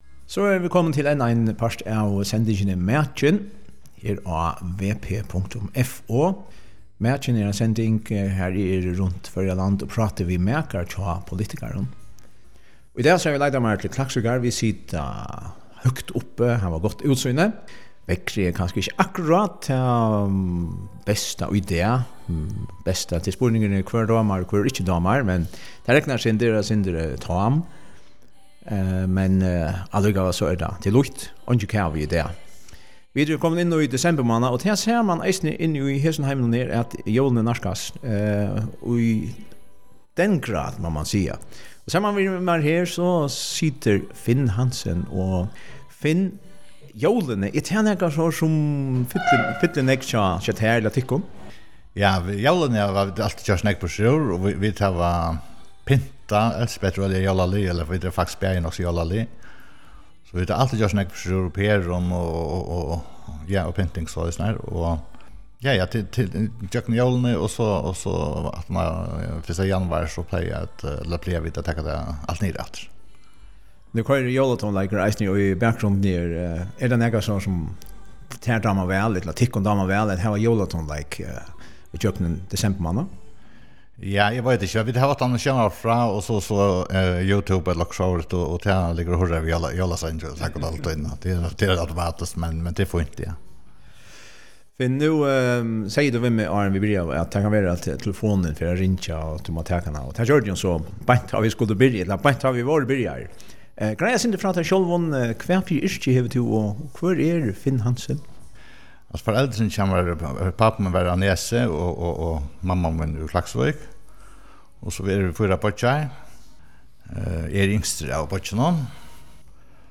Makin er sending har prátað verður við maka hjá politikara.